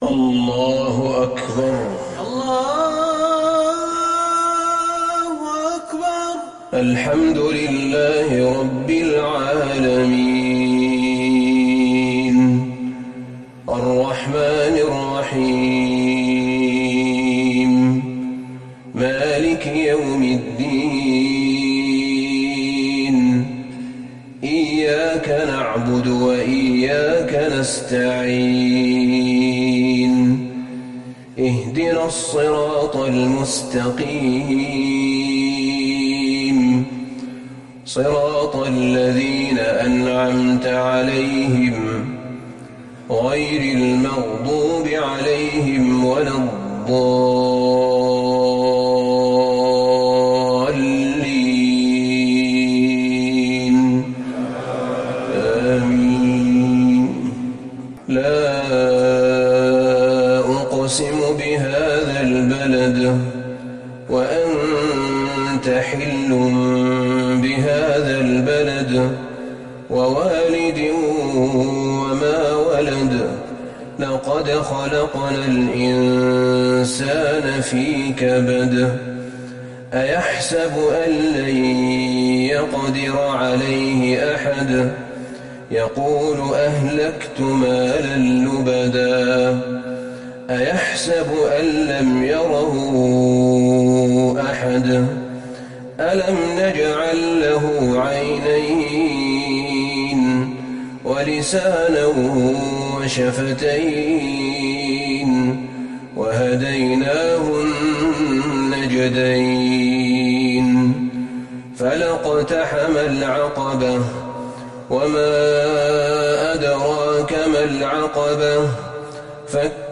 صلاة العشاء للشيخ أحمد بن طالب حميد 27 جمادي الأول 1441 هـ
تِلَاوَات الْحَرَمَيْن .